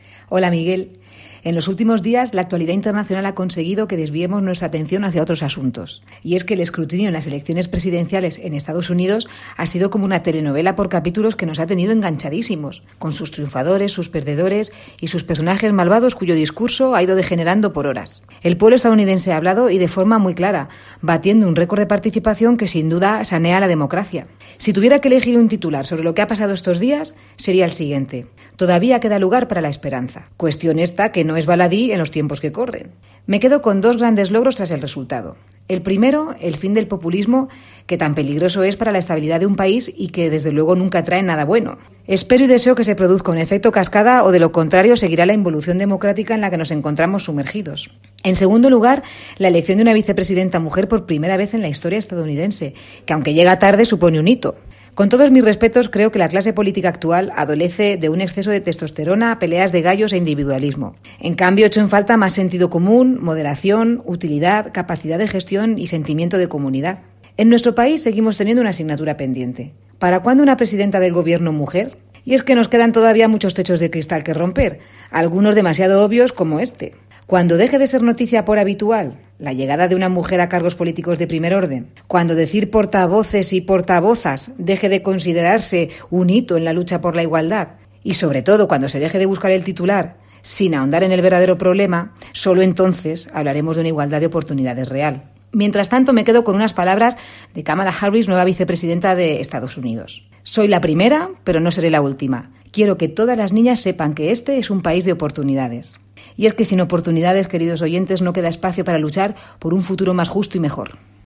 OPINIÓN